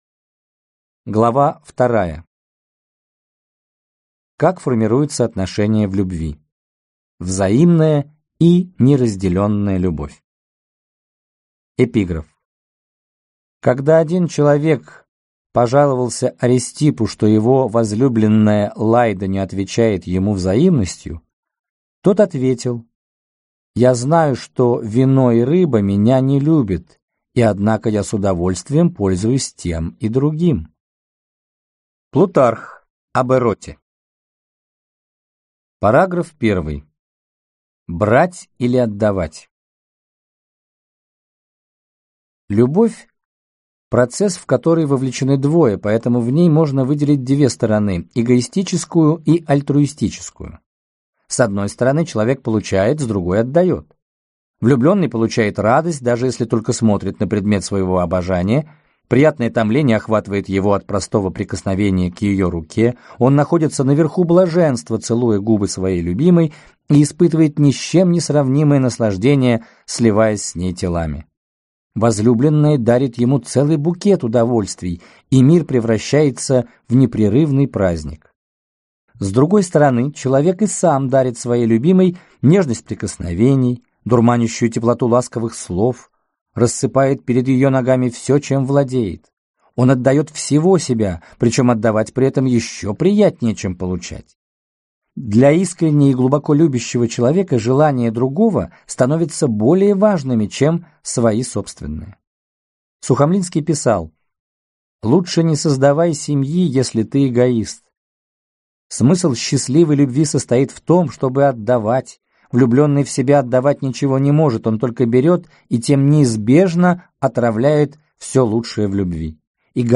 Аудиокнига Психология любви | Библиотека аудиокниг